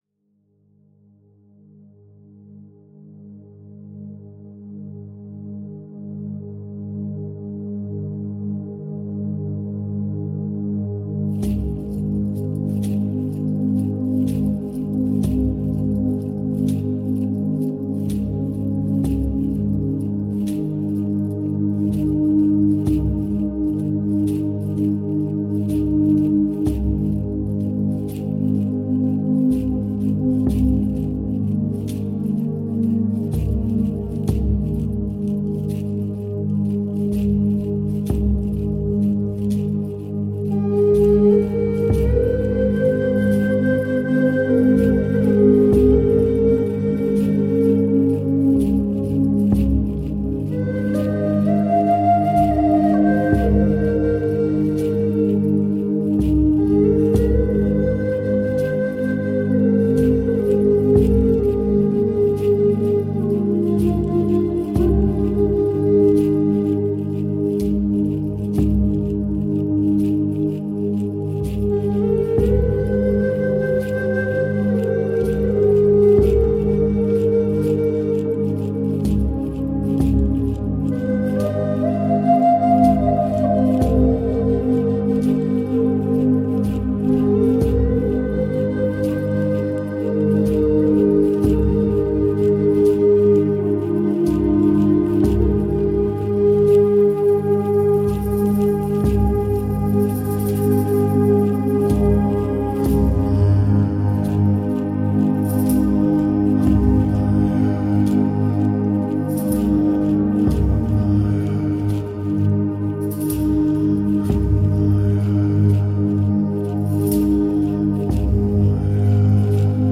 Tempo: 63 bpm